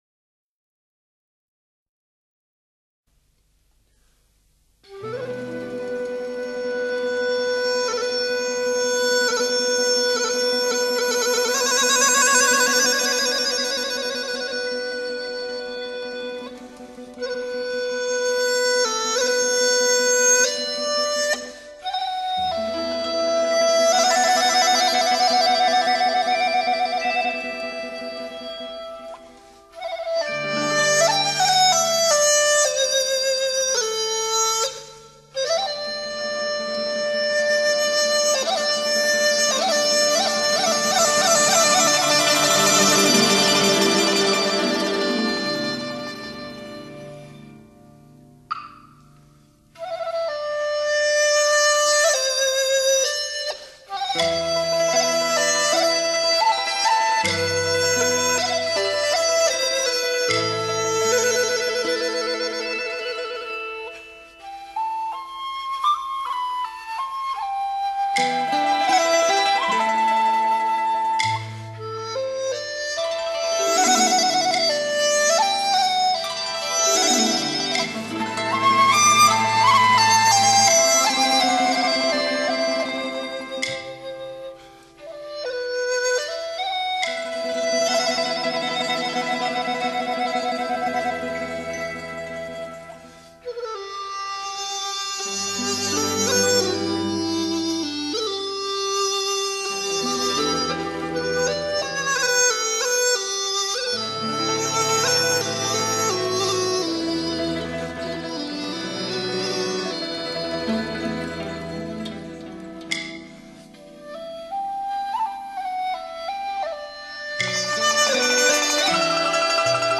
迂回婉转的音律，卓越深厚的演奏、憾人肺腑的乐魂、
这张专辑收录了笙、笛子和箫这三种吹奏乐器的经典曲目。